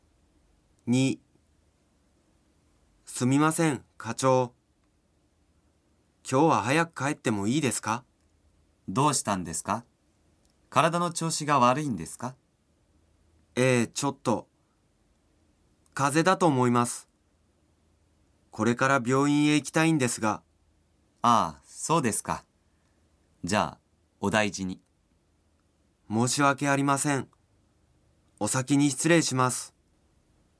会話　２
kaiwa_41-2.mp3